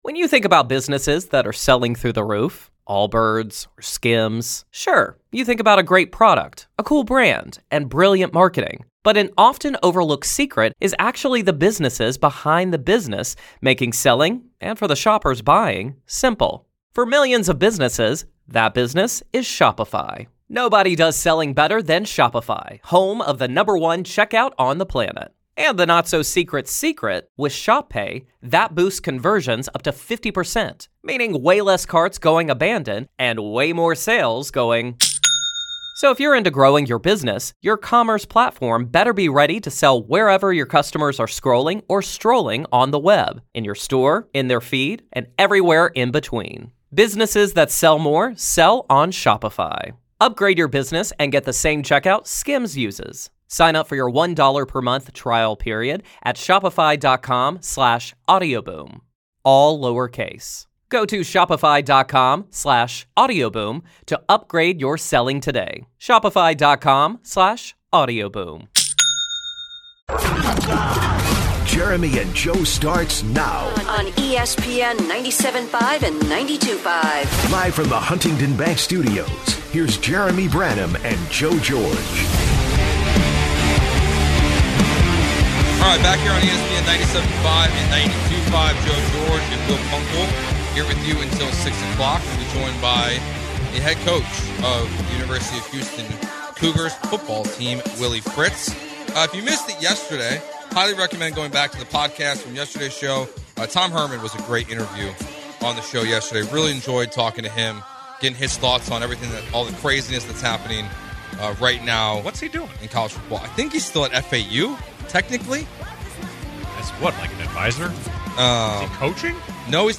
Head Coach Willie Fritz Joins the Show